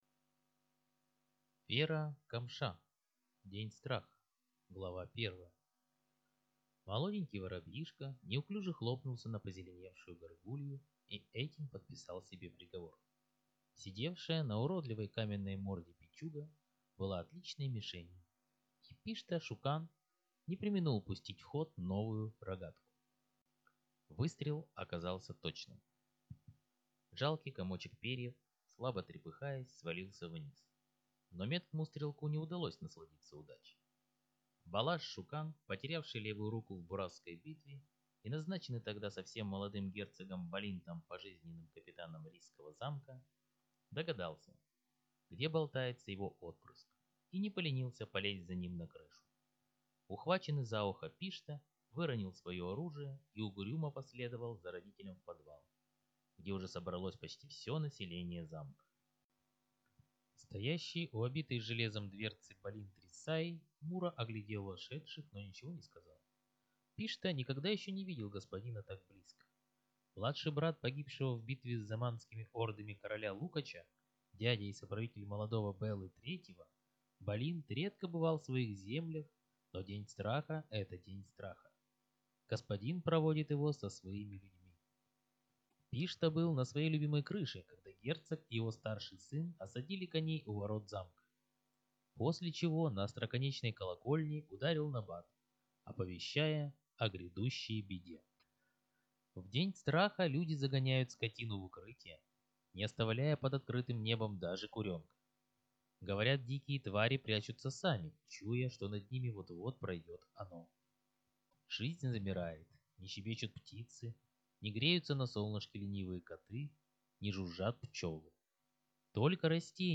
Аудиокнига День Страха | Библиотека аудиокниг
Прослушать и бесплатно скачать фрагмент аудиокниги